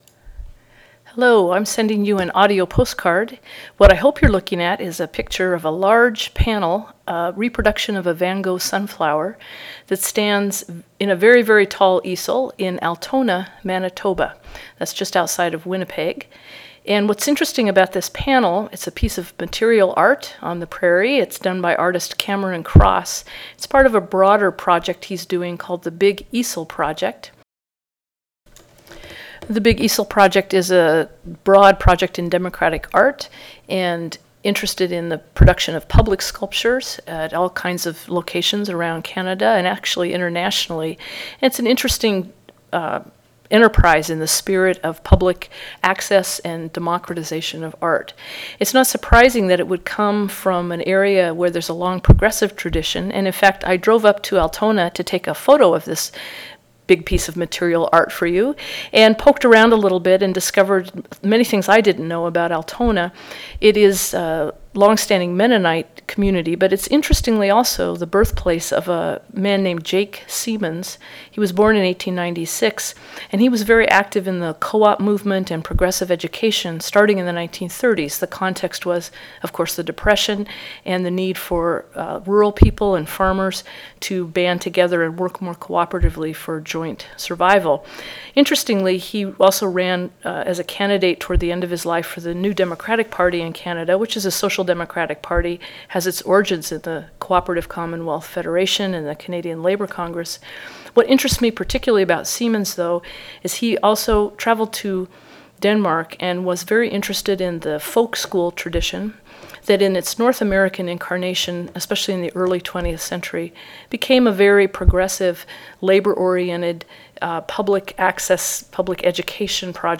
audio-postcard-for-canada-day-in-trier.wav